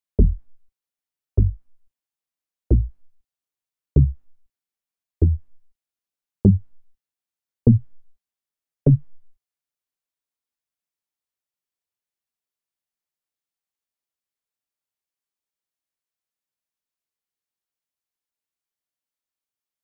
26_MoogDeepBass_F+3_1-2.wav